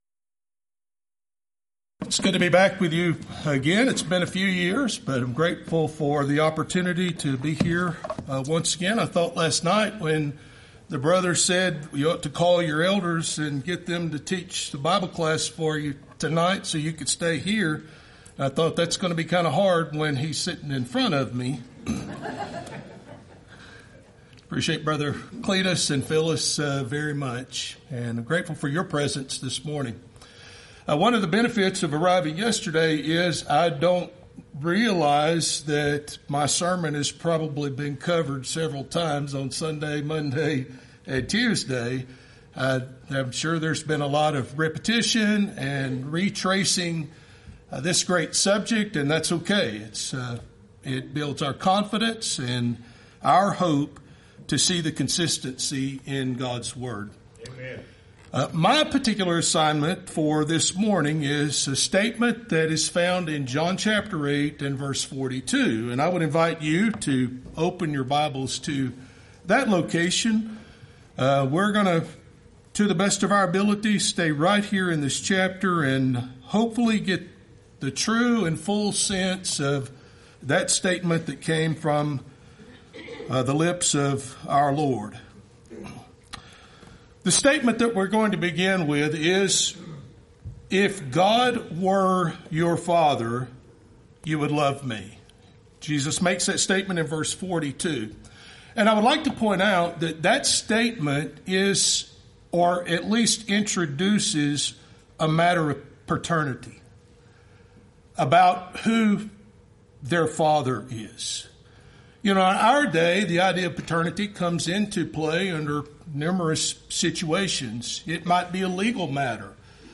Event: 26th Annual Lubbock Lectures Theme/Title: God is Love
lecture